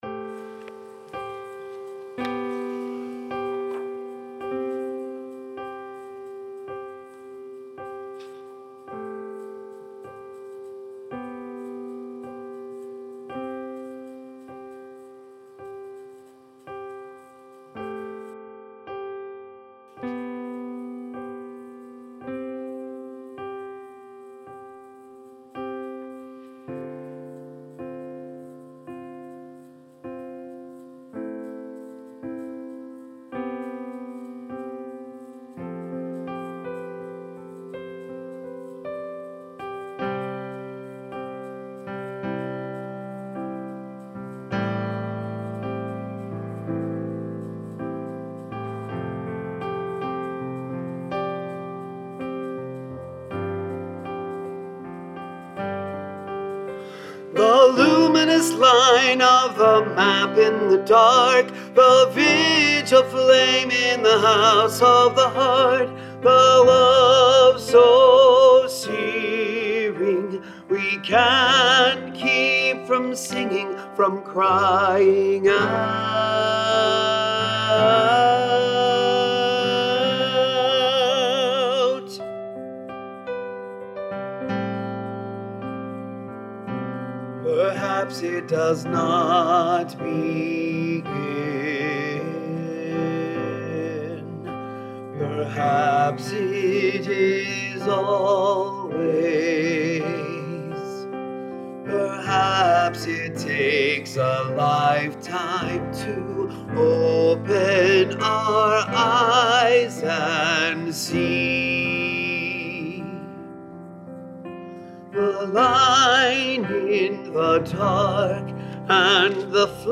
Rehearsal Recordings
Bass
Where the Light Begins Bass.mp3